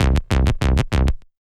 TI98BASS1 -L.wav